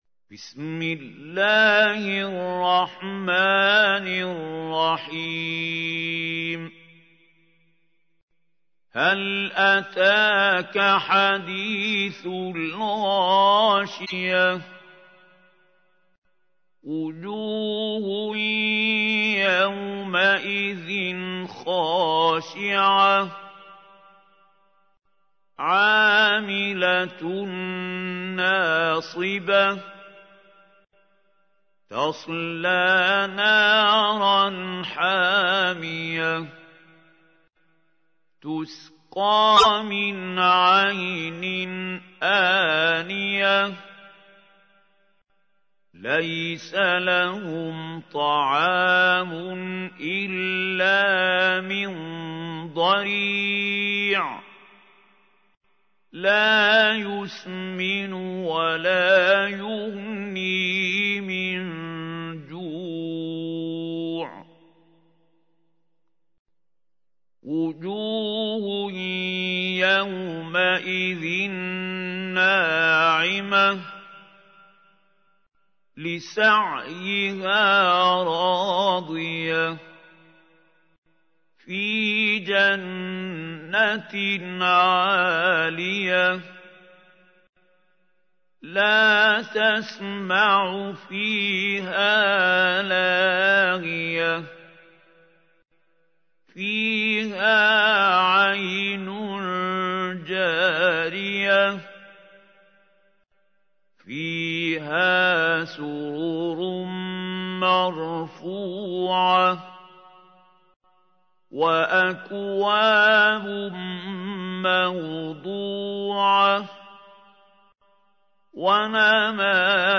تحميل : 88. سورة الغاشية / القارئ محمود خليل الحصري / القرآن الكريم / موقع يا حسين